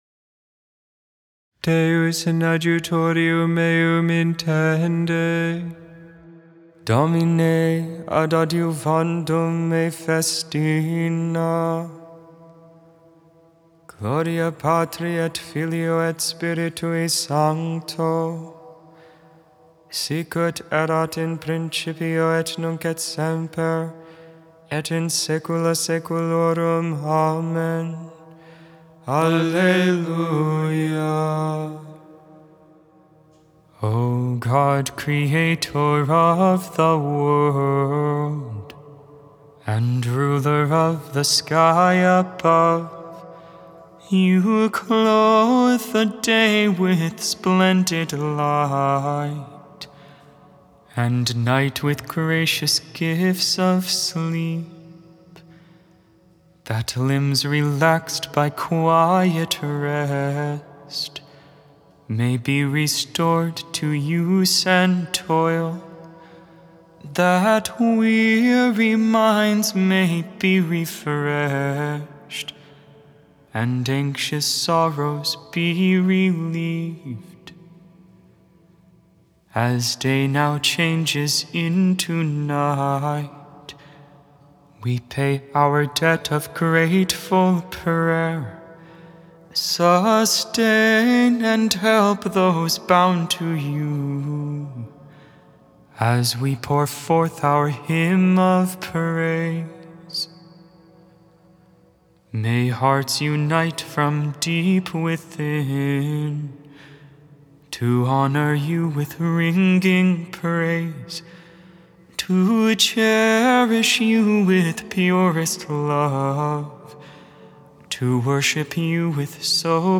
10.21.23 Vespers, Saturday Evening Prayer